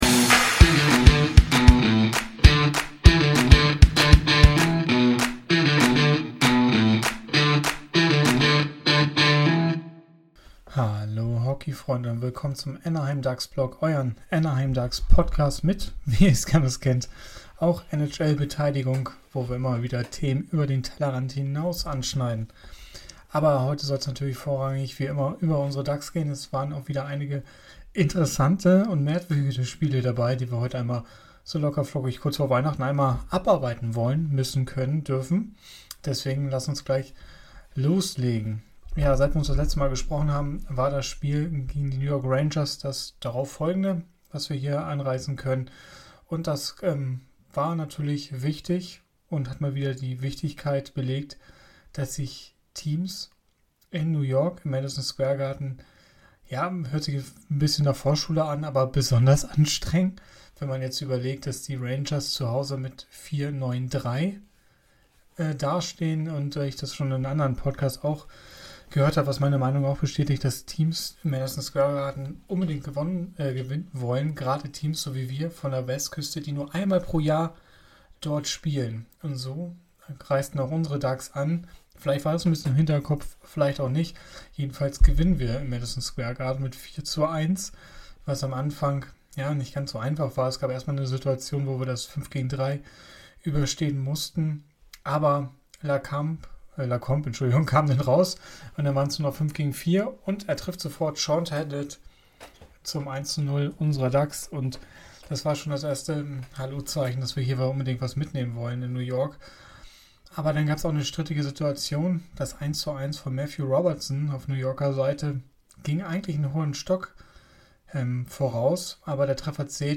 Hallo Hockeyfreunde, mit angeschlagener Stimme besprechen wir heute die jüngsten Spiele der Ducks und analysieren die aktuelle Situation. Dazu schauen wir genau auf die Tabelle und besprechen die Wichtigkeit der nächsten Spiele.